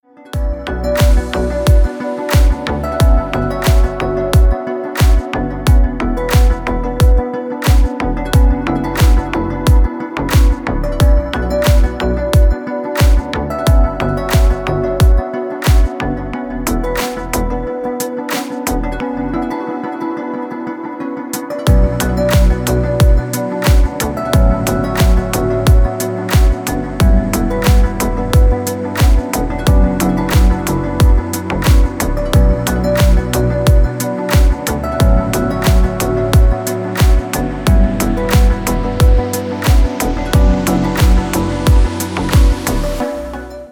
• Качество: Хорошее
• Песня: Рингтон, нарезка
• Категория: Красивые мелодии и рингтоны